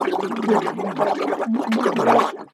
Gargle Drown Water Bubbles Sound
human